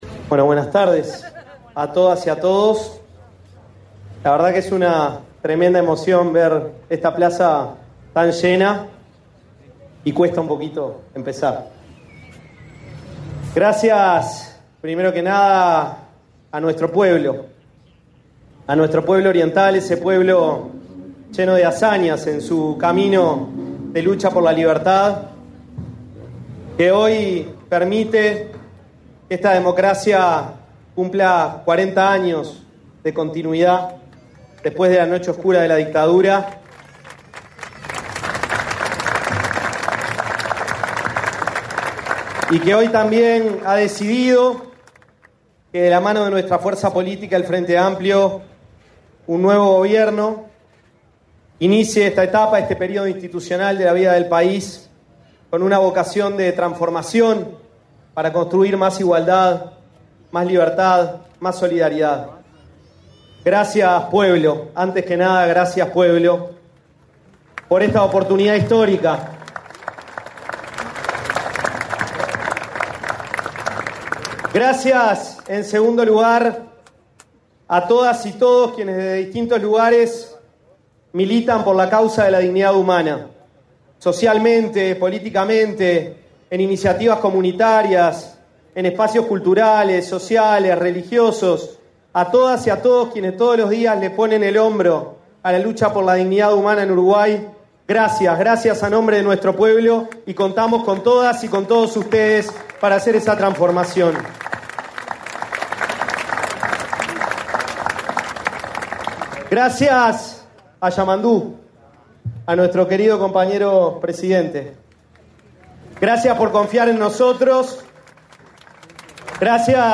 Palabras del ministro de Desarrollo Social, Gonzalo Civila
Palabras del ministro de Desarrollo Social, Gonzalo Civila 06/03/2025 Compartir Facebook X Copiar enlace WhatsApp LinkedIn El presidente de la República, profesor Yamandú Orsi, junto a la vicepresidenta, Carolina Cosse, participó, este 6 de marzo, en la ceremonia de asunción de autoridades del Ministerio de Desarrollo Social, realizada en la plaza Huelga General de 1973, en Montevideo. Gonzalo Civila asumió como ministro y Federico Graña, como subsecretario.